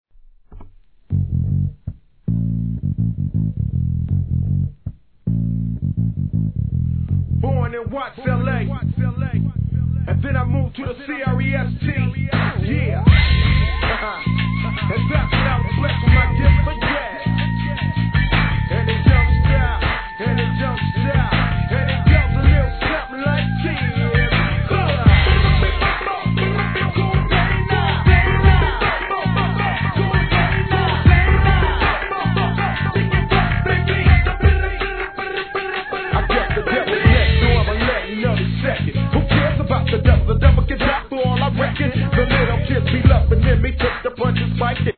G-RAP/WEST COAST/SOUTH
通好みな極上FUNK仕上げの好EP盤が再入荷!!です!!